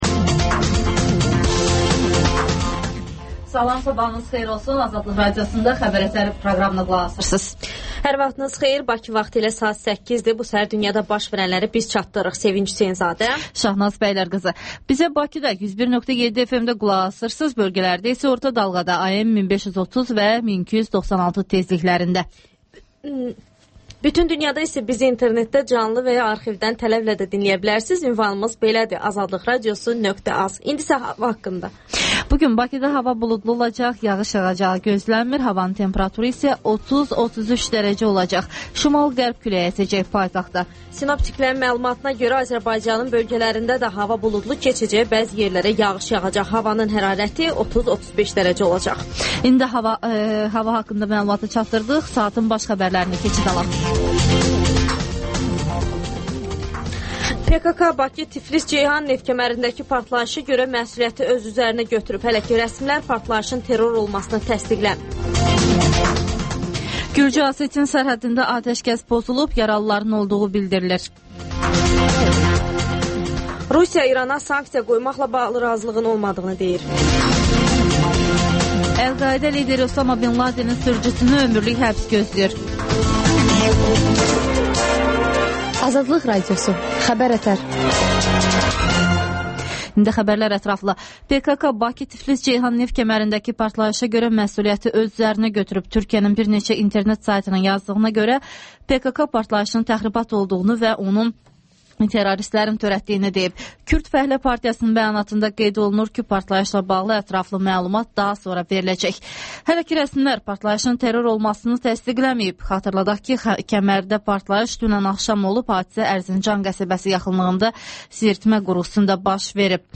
Xəbər-ətər: xəbərlər, müsahibələr, sonda 14-24: Gənclər üçün xüsusi veriliş